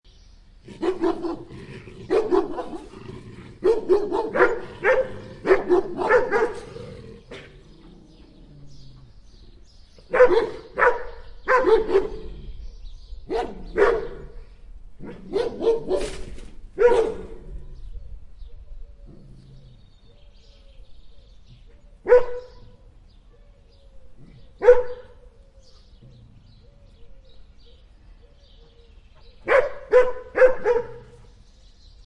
Two Dogs Barking Sound Button - Free Download & Play